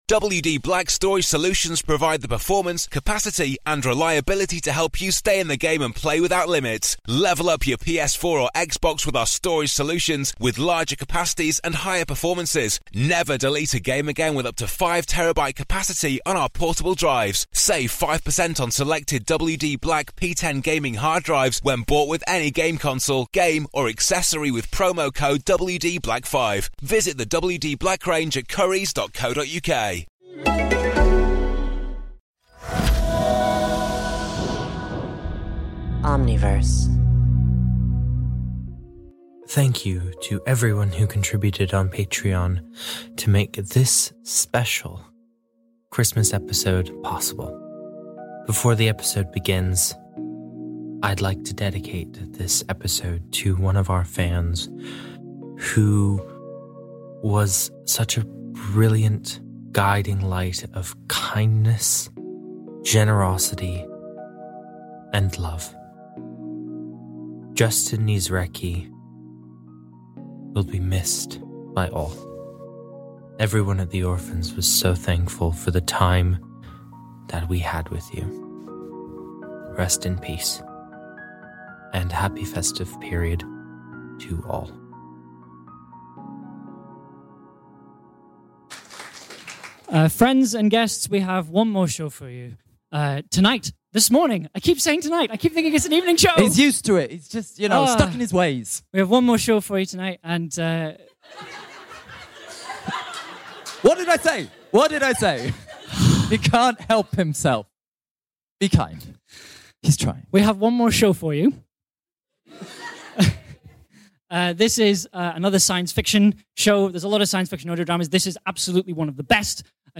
A live holiday special recorded at London Podcast Festival Presents Audio Drama!